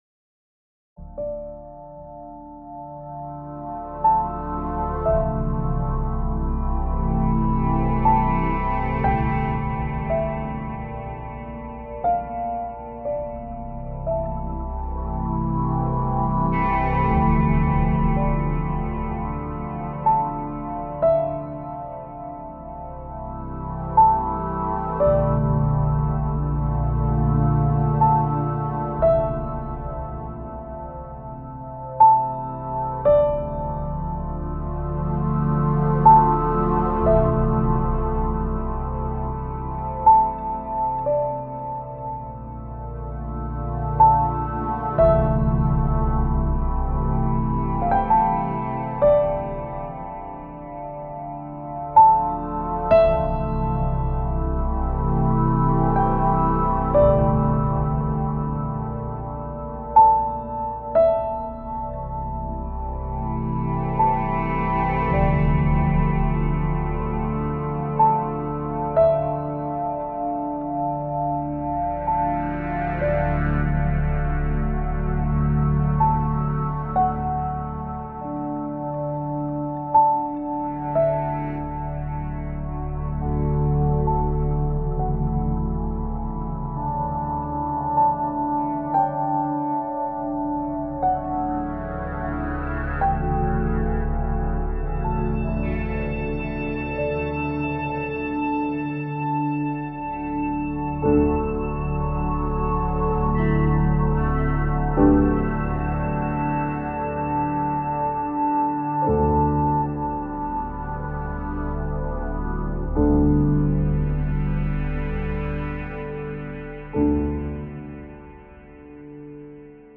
Imrovisationen an einem Nachmittag… Logic Pro und Fender Tele…